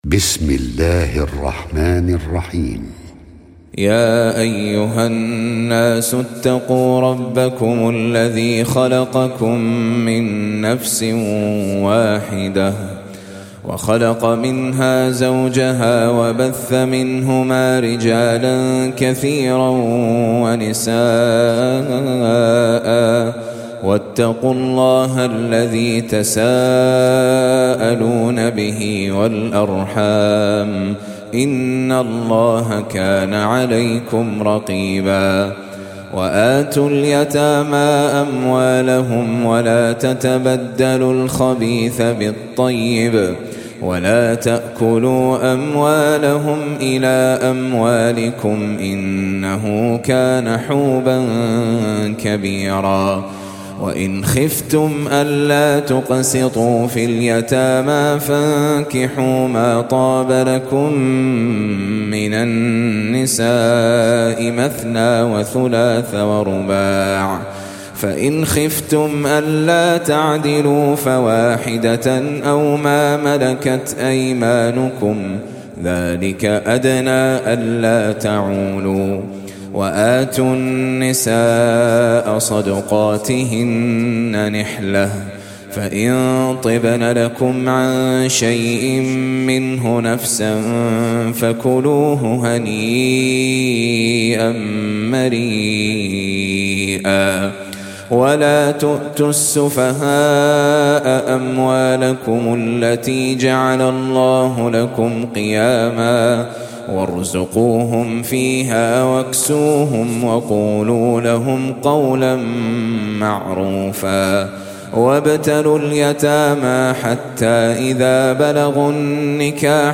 4. Surah An-Nis�' سورة النساء Audio Quran Tajweed Recitation
Surah Sequence تتابع السورة Download Surah حمّل السورة Reciting Murattalah Audio for 4.